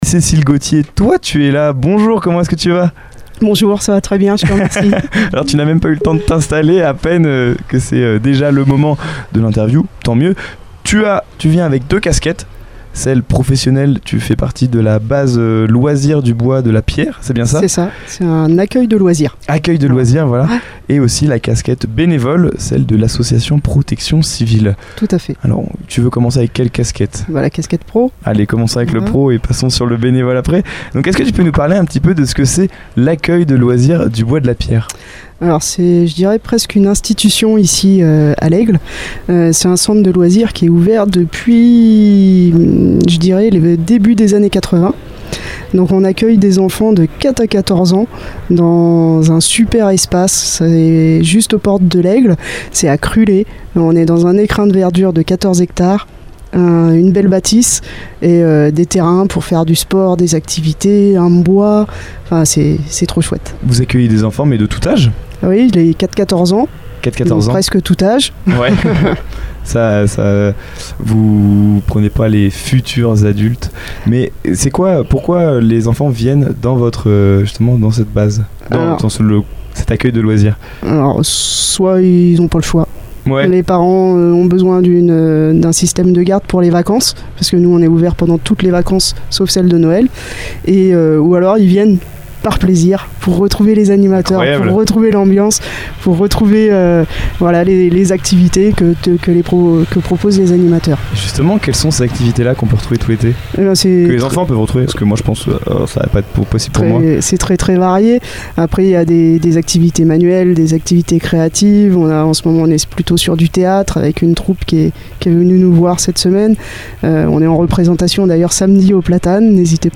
Elle évoque également son engagement au sein de la Protection Civile, entre prévention, formation aux gestes qui sauvent et interventions lors d’événements. Une interview engagée et humaine qui met en lumière le dévouement au service des autres, que ce soit auprès des enfants ou dans l’action citoyenne.